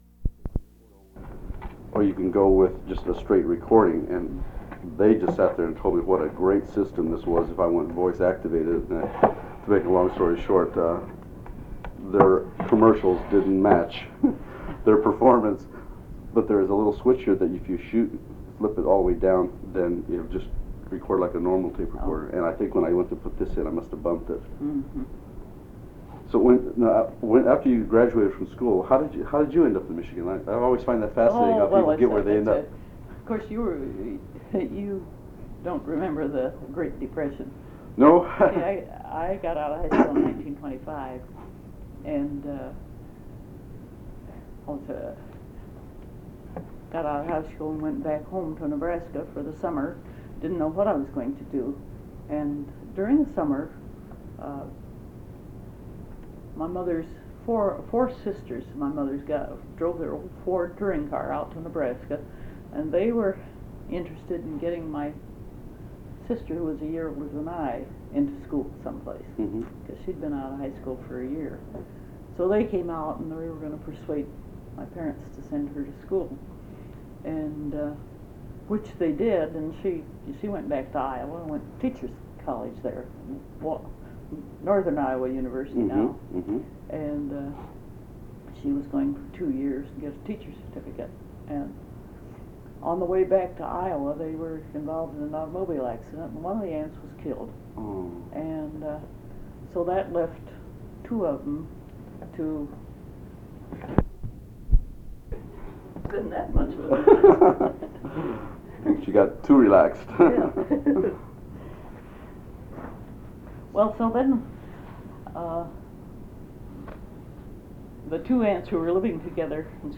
Date: March 31, 1990 Format: Audio/mp3 Original Format: Audio cassette tape Resource Identifier: A008655 Collection Number: UA 10.3.156 Language: English Rights Management: Educational use only, no other permissions given.